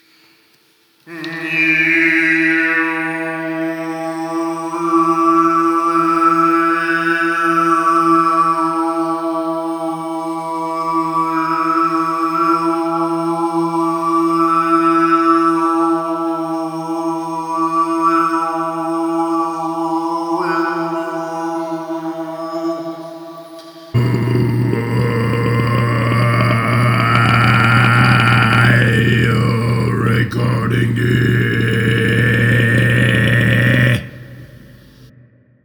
da singe ich meine obertöne doch lieber selber ein...
Warum eigentlich Oberton-Gesang?
Klingt eher wie Unterton-Gesang.
overtone.mp3